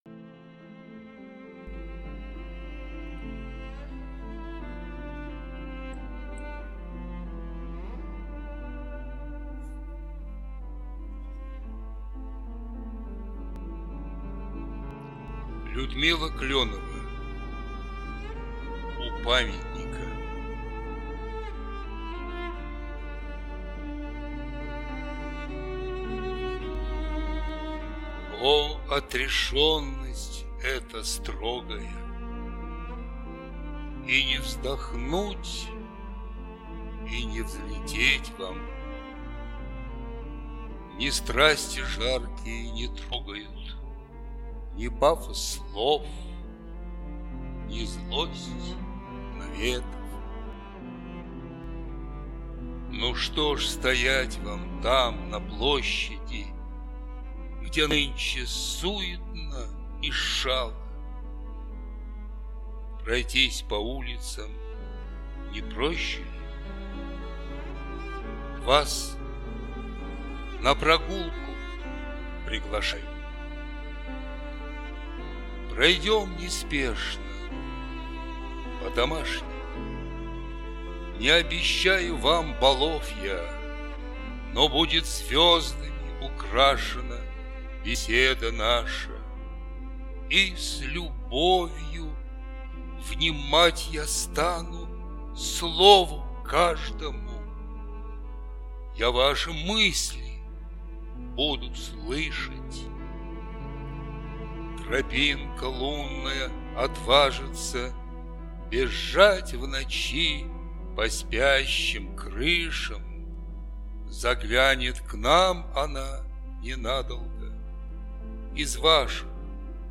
декламация